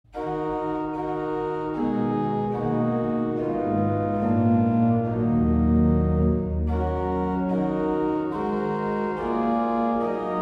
Der Klang der Ehrlich-Orgel in der Stadtkirche hat seine Wurzeln im mainfränkischen Orgelbau der Barockzeit. In seiner Farbigkeit wirkt er auf heutige Ohren meist auf das erste eher ein wenig fremd und archaisch.